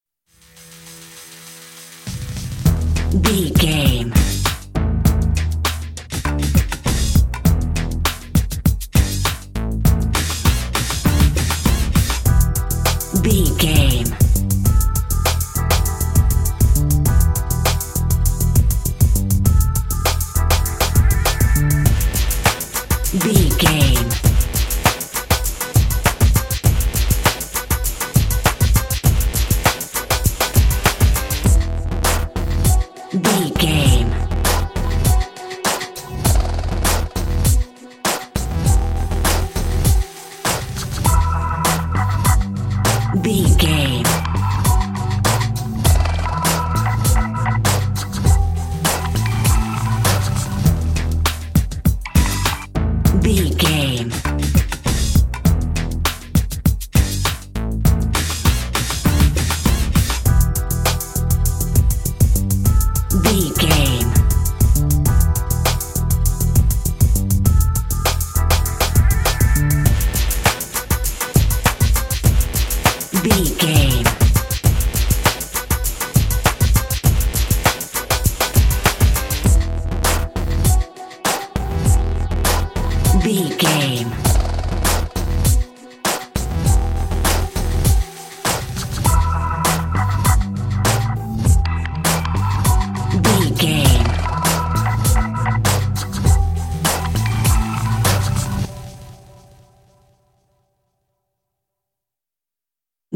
Aeolian/Minor
synthesiser
drum machine